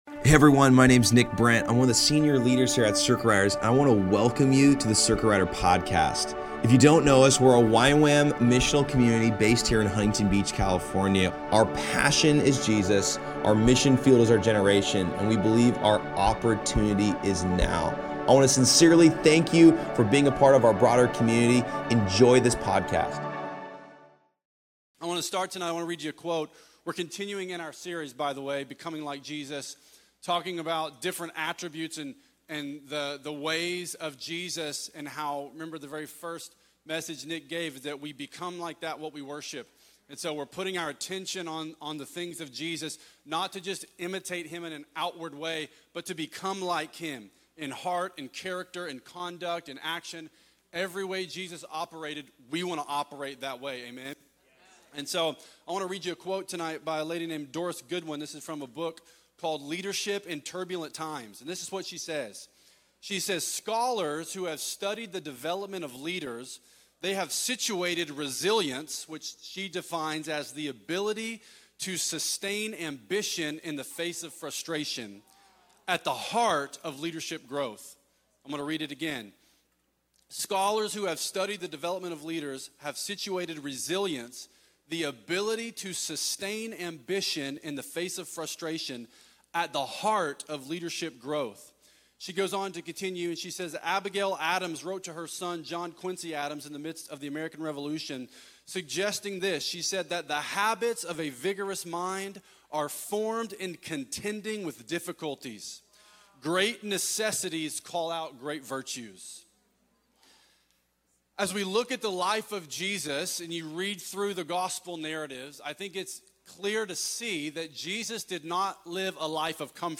At Circuit Riders Monday Nights
Location Costa Mesa
Message